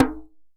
DJEM.HIT02.wav